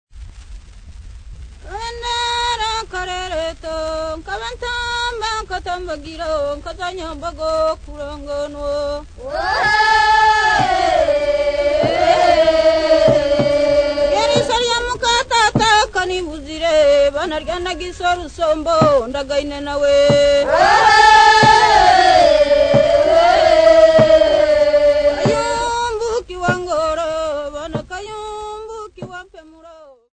Zinza women
Folk Music
Field recordings
Africa Tanzania City not specified f-tz
Indigenous music